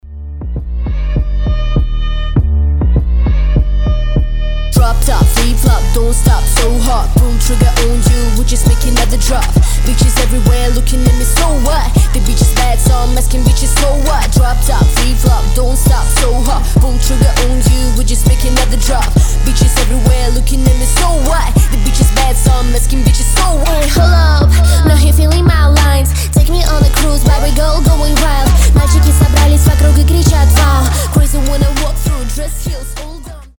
рэп , trap
сирена , басы , крутые , громкие